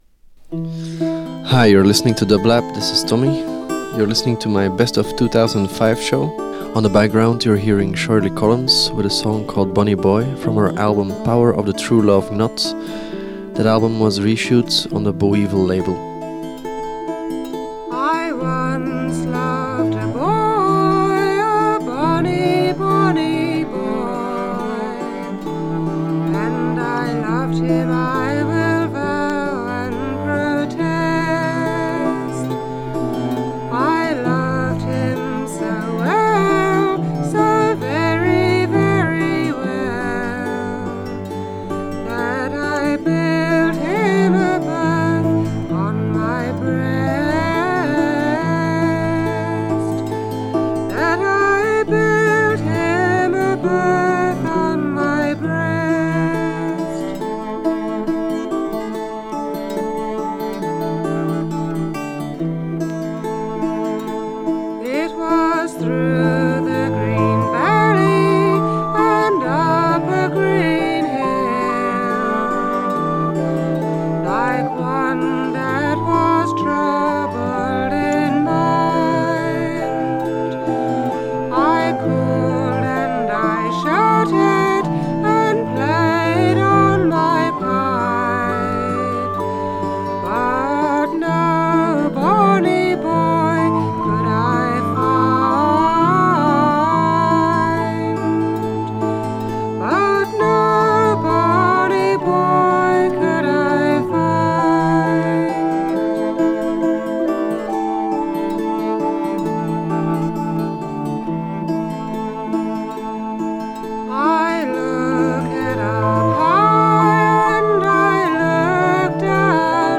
Avant-Garde Electronic Psych